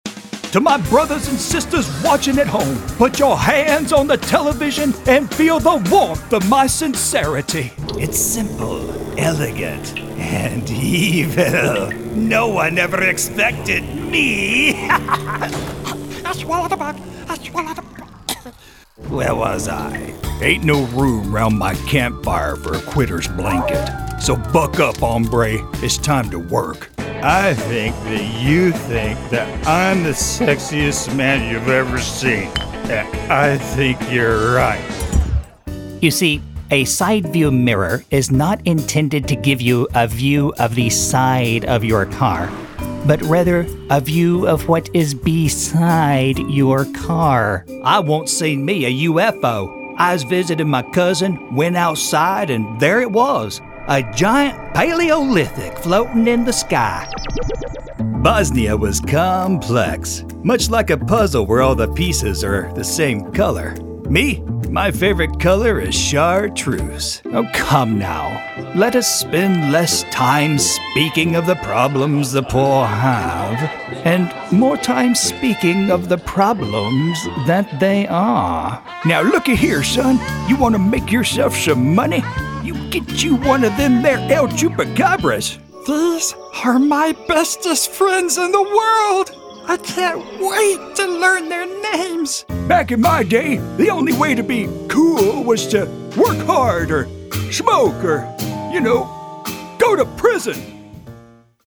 U.S. Texan, Mid Atlantic, Southern and many more
Middle Aged
Senior
Commercial